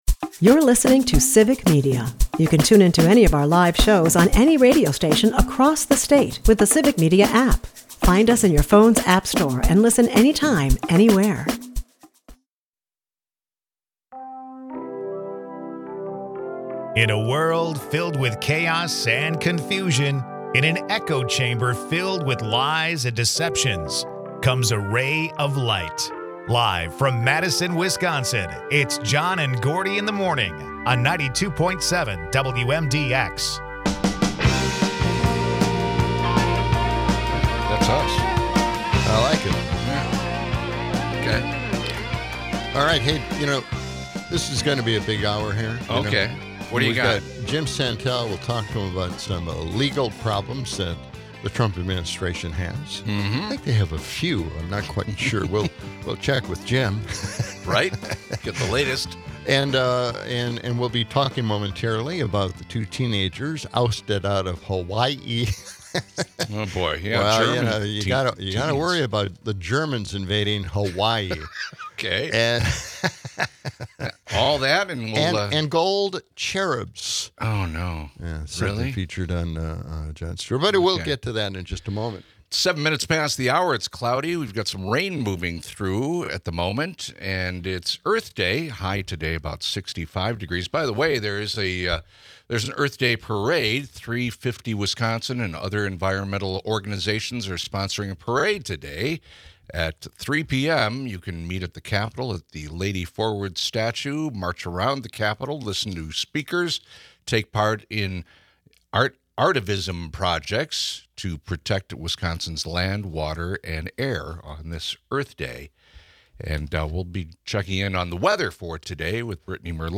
A lively mix of legal insights, comedic banter, and a sprinkle of absurdity.